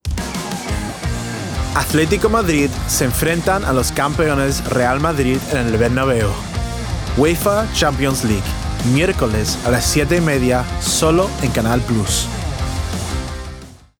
Promo, Cool, Confident, Energy